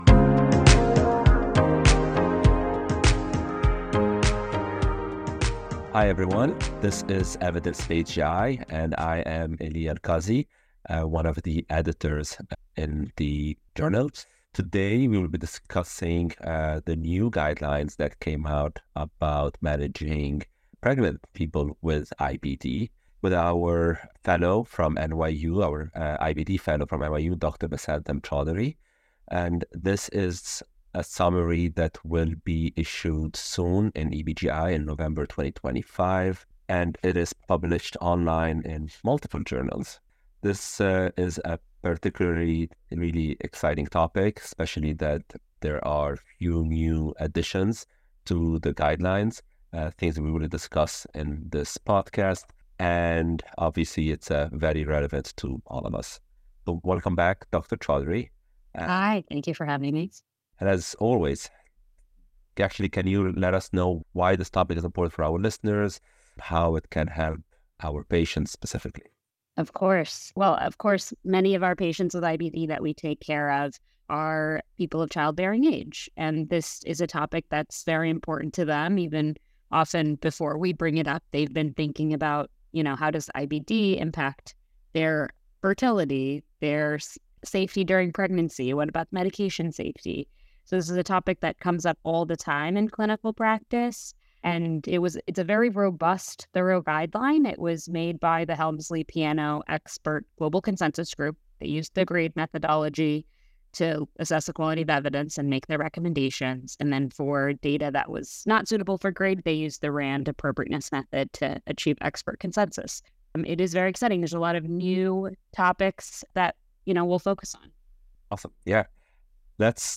interviews Associate Editors who review recently published articles related to the field of GI published in non-GI journals.